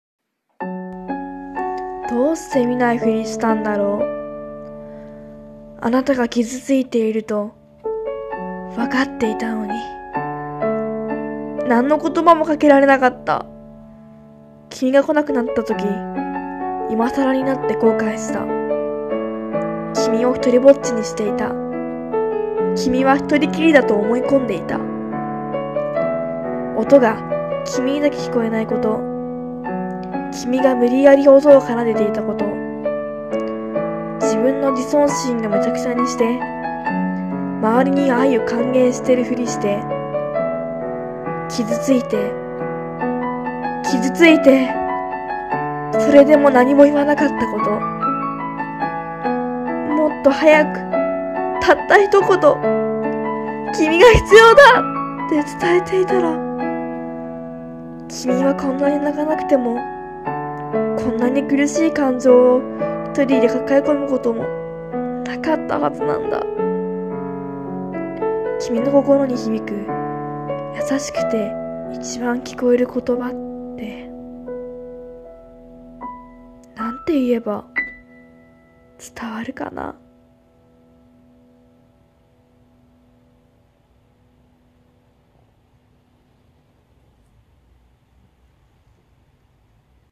心に響く言葉【朗読】